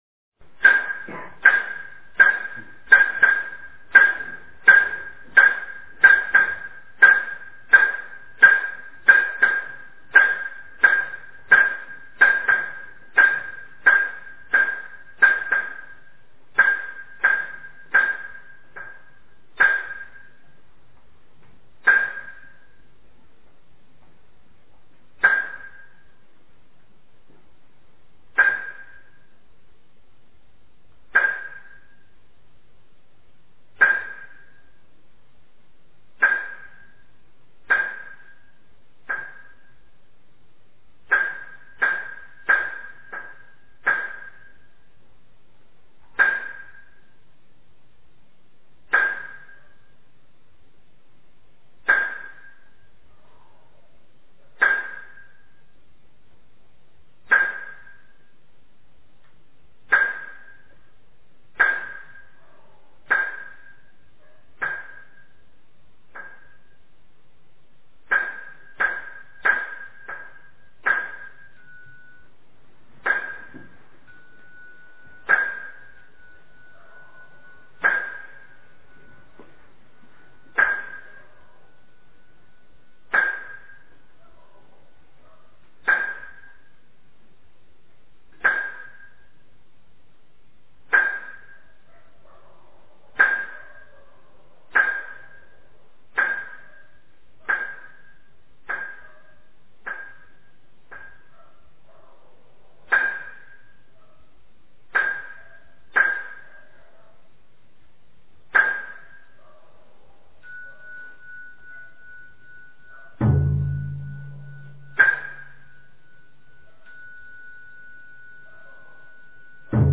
早课--龙泉寺 经忏 早课--龙泉寺 点我： 标签: 佛音 经忏 佛教音乐 返回列表 上一篇： 早课--灵泉禅寺版 下一篇： 阿弥陀佛-超长版--精进佛七 1小时 相关文章 南无消灾延寿药师佛圣号--中国佛学院 南无消灾延寿药师佛圣号--中国佛学院...